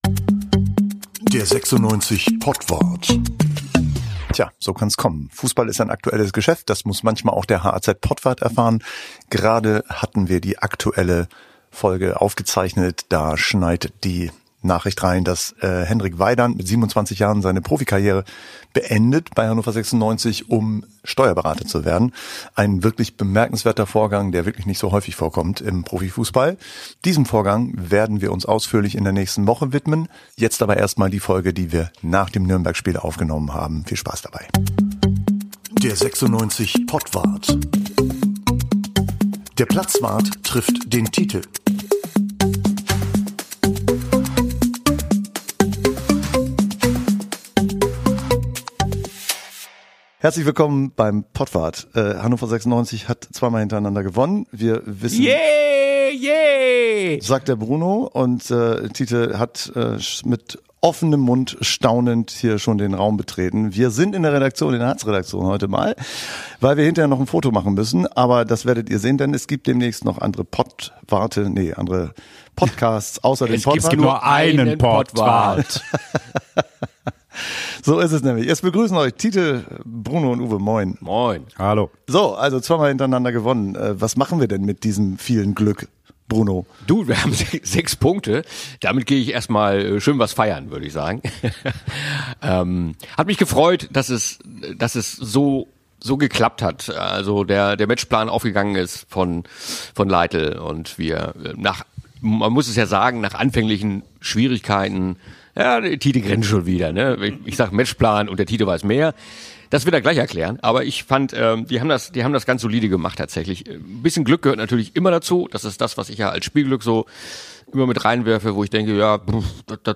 und zwar dieses Mal in der HAZ-Redaktion. Bei viel Kaffee und bester Laune geht es um die Seriensieger vom Maschsee, den besten Linksverteidiger der Liga, der allerdings kein Verteidiger ist, und um die Frage, ob Trainerwechsel funktionierten – und warum nicht.